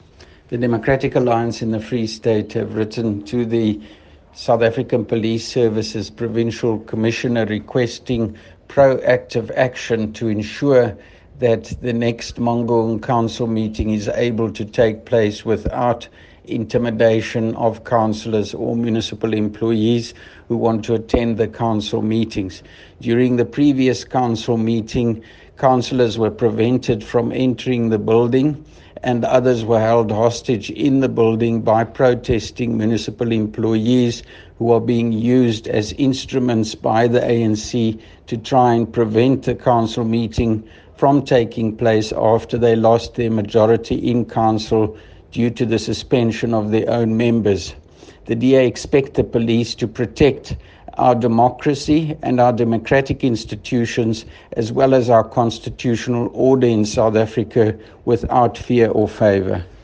Afrikaans soundbites by Dr Roy Jankielsohn MPL.